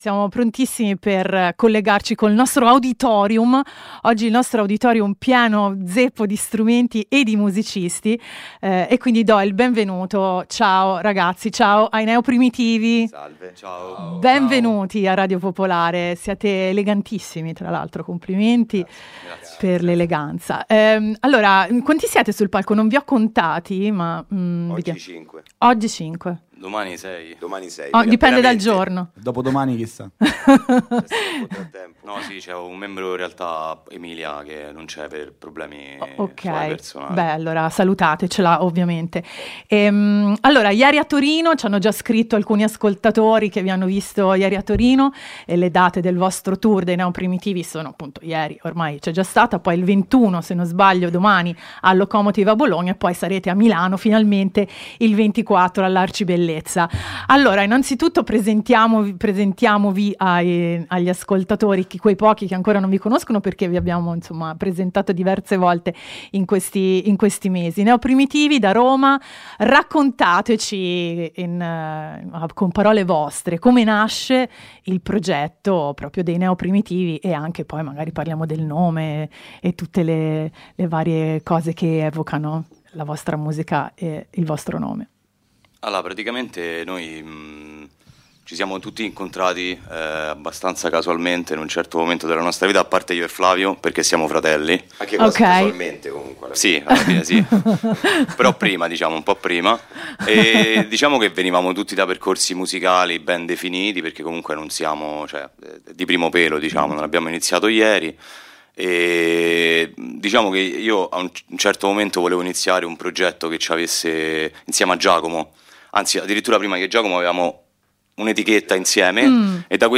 band-collettivo romano
suonato il lato A del disco nel nostro auditorium